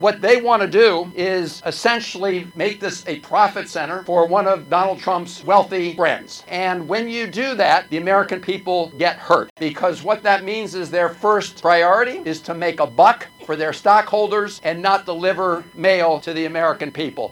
U.S. Senator Chris Van Hollen joined postal workers on Capitol Hill during a rally to protest possible changes to the United States Postal Service under President Trump.